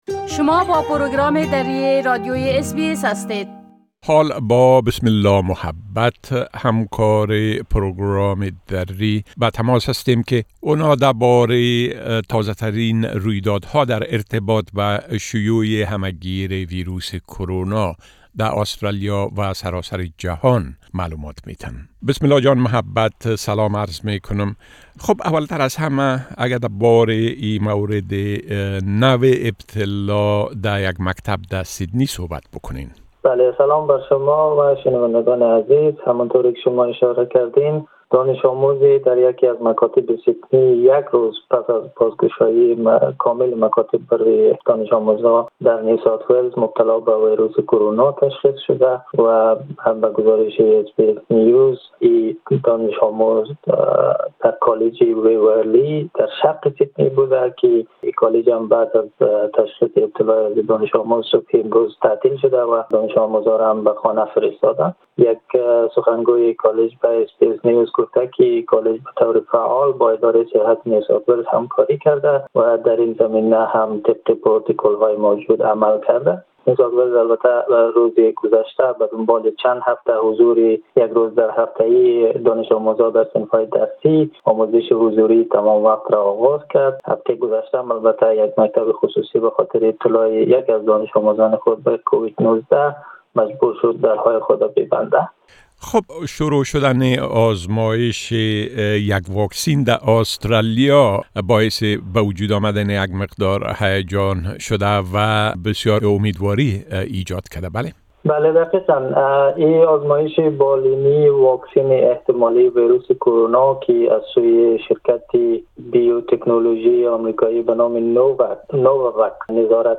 در این گفت‌وگو: